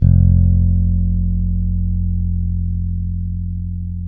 -MM JAZZ F 2.wav